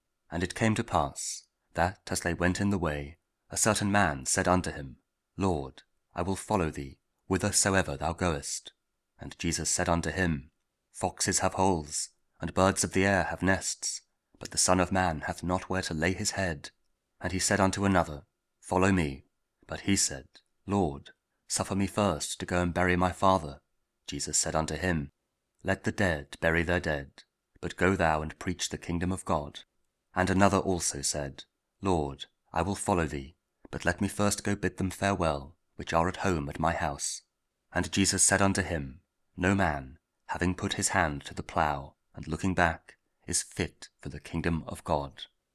Audio Daily Bible